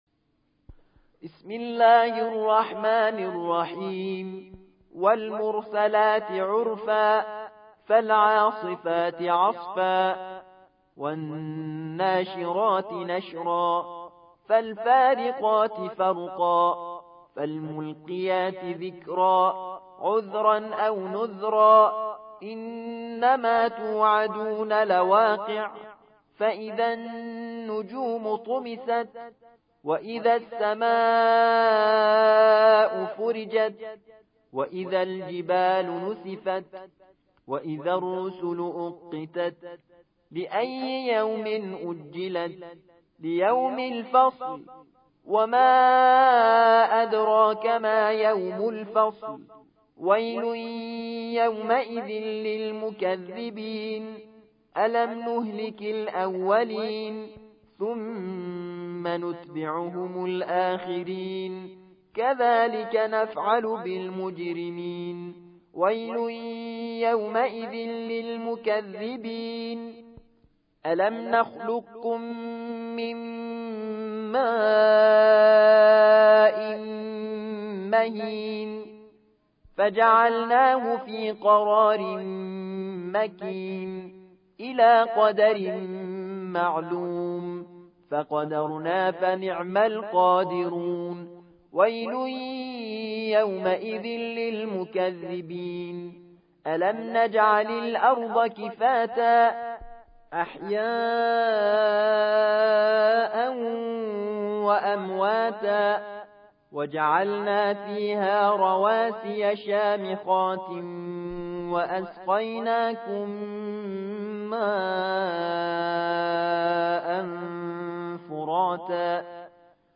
77. سورة المرسلات / القارئ